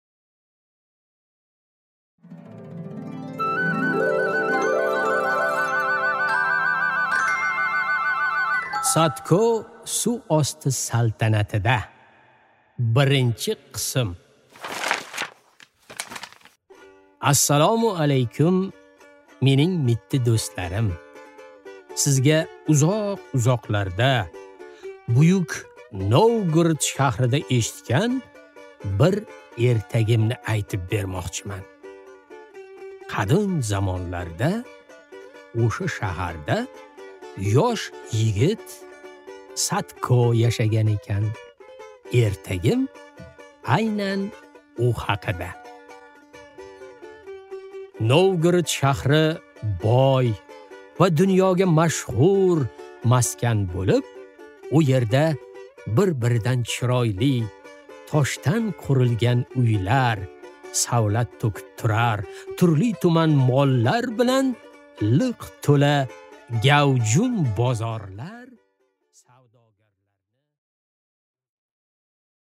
Аудиокнига Sadko suv osti saltanatida